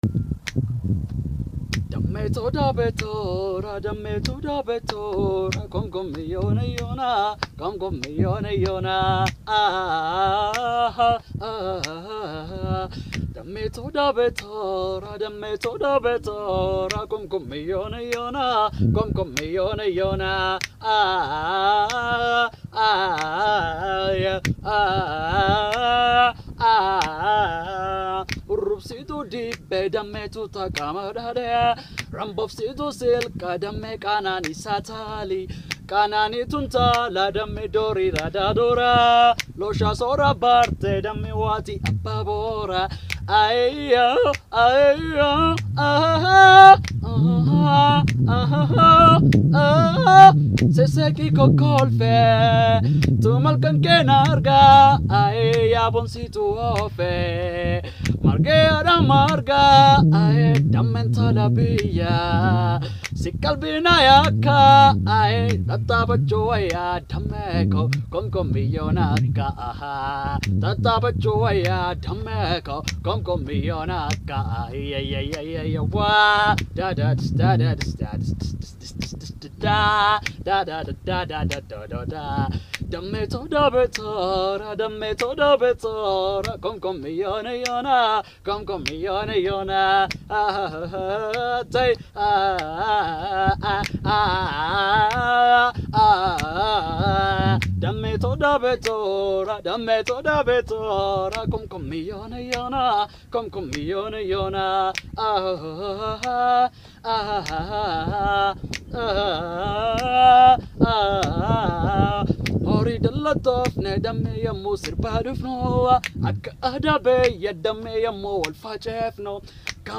oromo music Cover